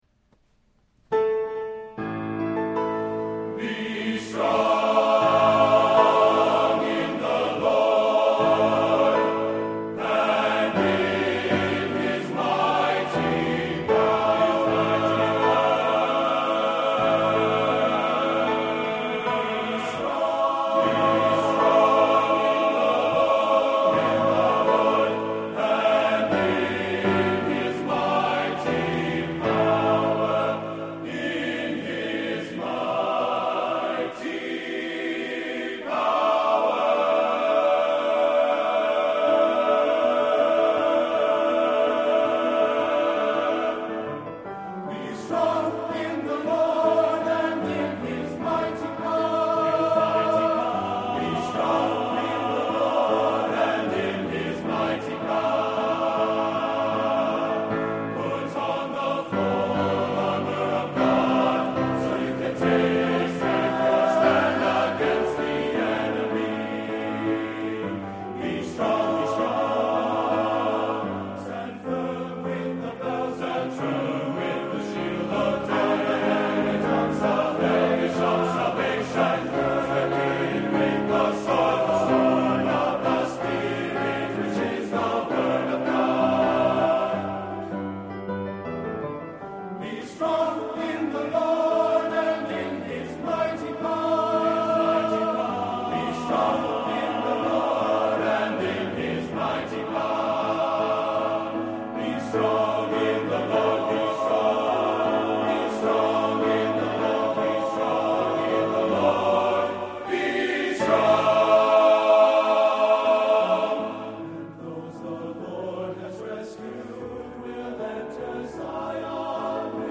Voicing: SATB and Solo